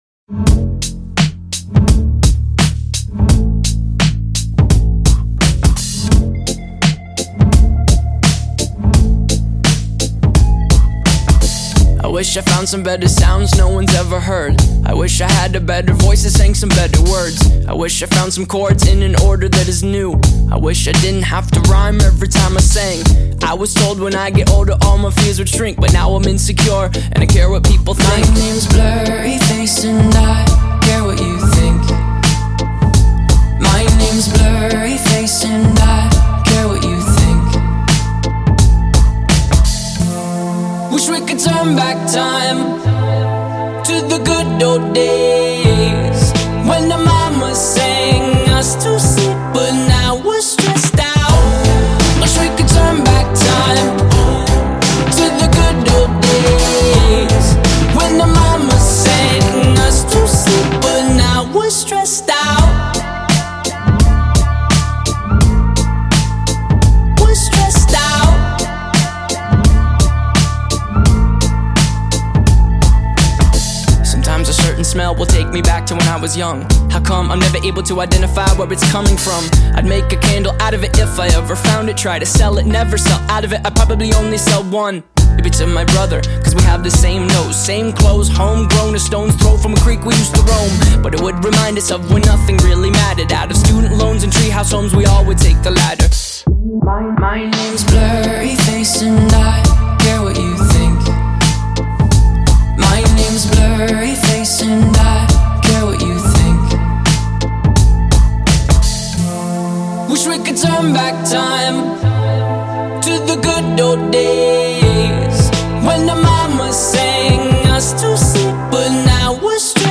Rock 2010er